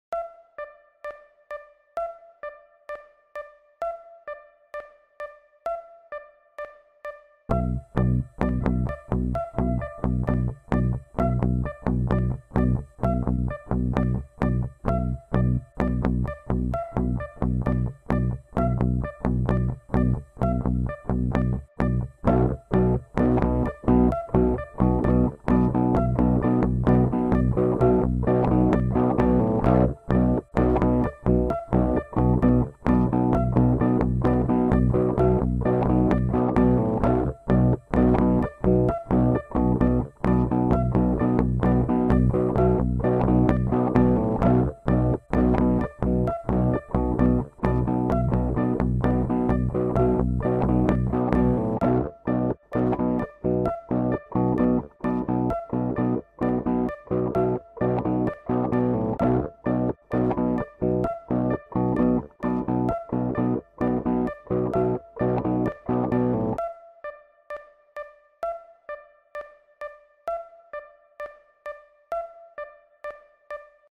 Il n'y a que des "Si", pas de quinte, de powerchord et encore moins de tierce : que des Si !
Celle dite "2" se joue plus dans l'aigu.
[0'00] métronome, [0'07-0'52] la partie 1 à gauche et [0'22-1'07] la partie 2 à droite du panoramique.
On entend : 4 mesures à 4 temps (la longueur d'un cycle) avec le métronome, puis 2 cycles avec la partie 1 seule, puis 4 fois la partie 1+2, puis 2 fois la partie 2 toute seule.